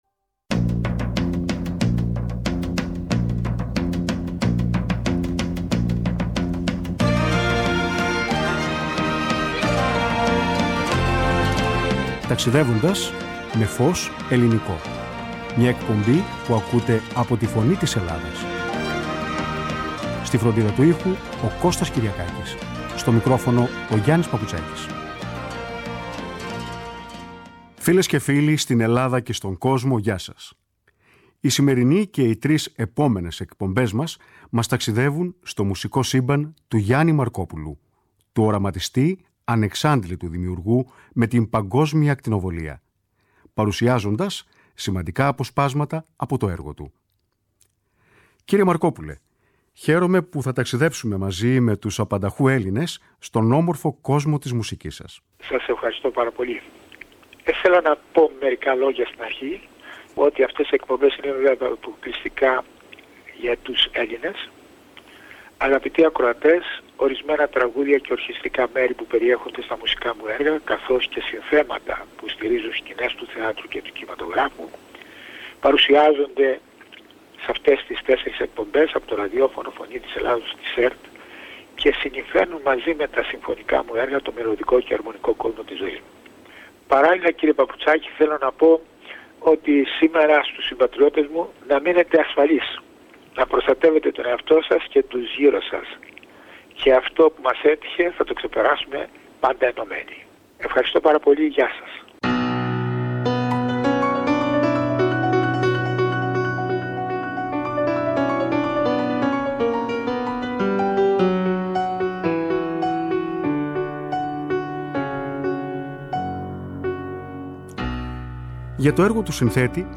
παρουσιάζονται τέσσερις συλλεκτικές εκπομπές όπου ο ίδιος ο δημιουργός επέλεξε αντιπροσωπευτικές μουσικές και αντιπροσωπευτικά τραγούδια από το σύνολο του έργου του.